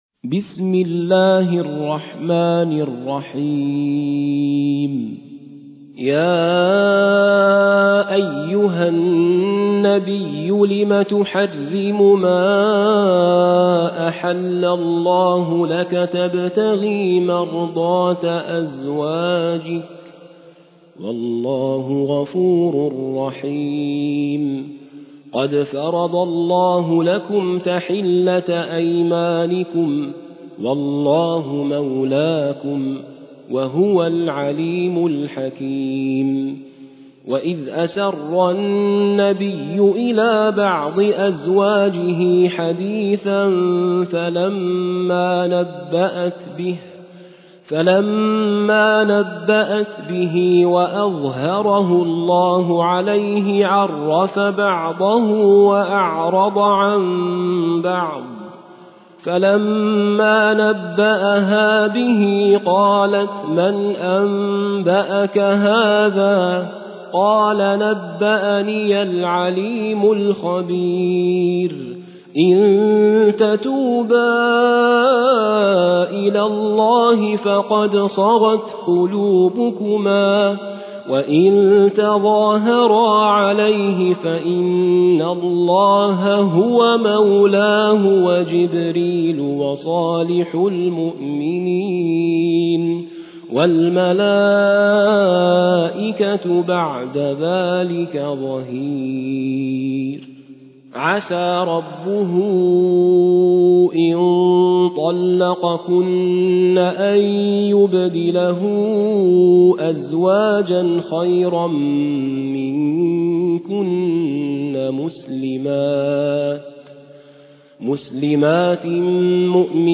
High Quality Quranic recitations and Islamic Lectures from selected scholars
المصحف المرتل - حفص عن عاصم - At-Tahrim ( The Prohibition )